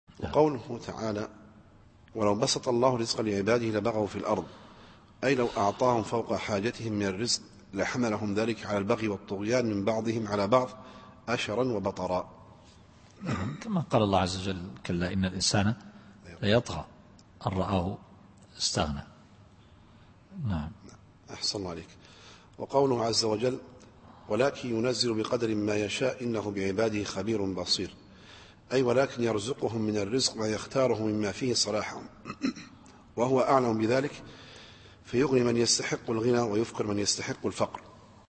التفسير الصوتي [الشورى / 27]